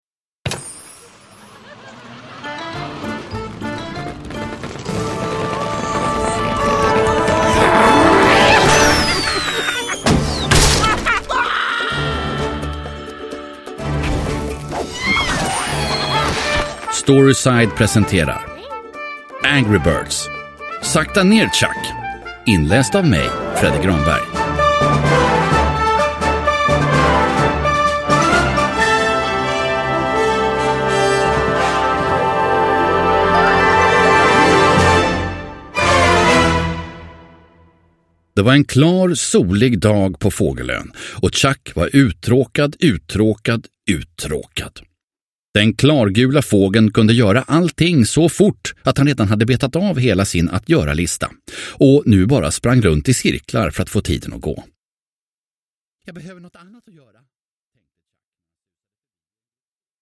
Angry Birds - Sakta ner, Chuck! – Ljudbok – Laddas ner